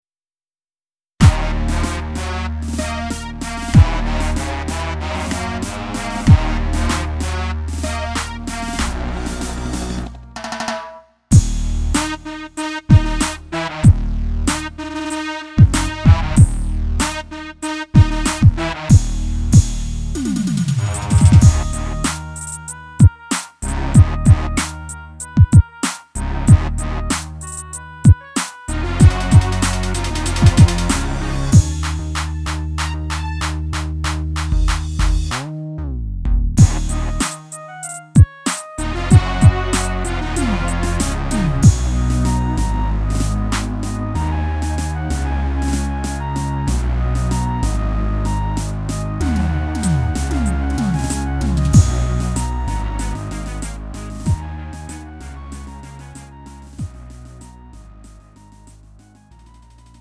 음정 -1키 2:58
장르 가요 구분 Lite MR